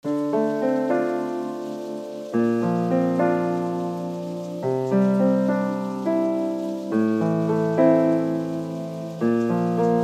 Минуса рэп исполнителей